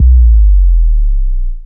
Low End 12.wav